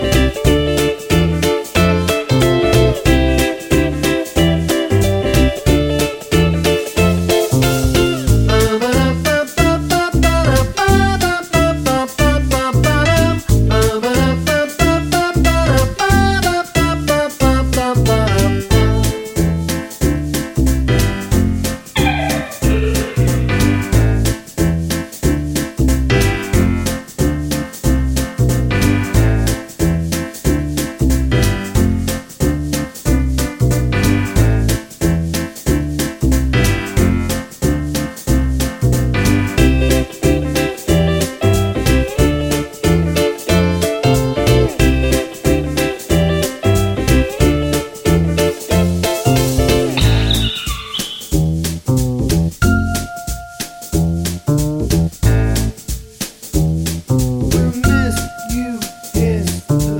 no Backing Vocals Indie / Alternative 3:38 Buy £1.50